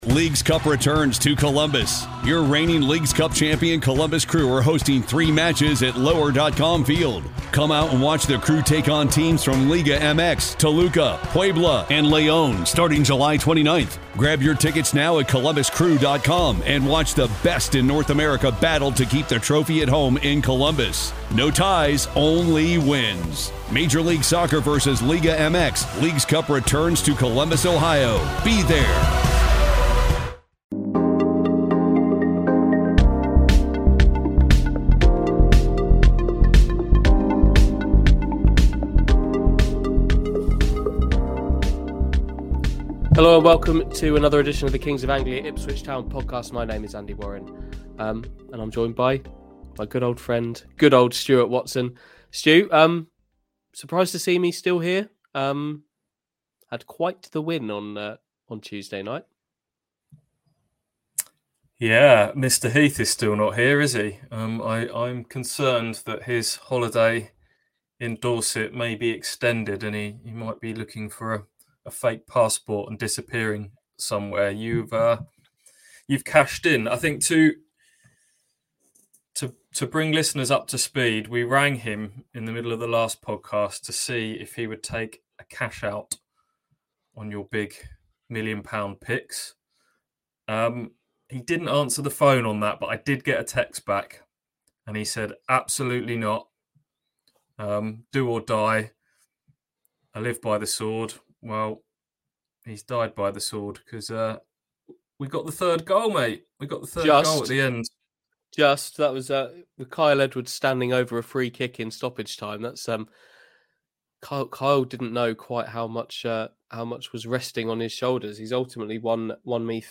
We get him on the phone to sort things out.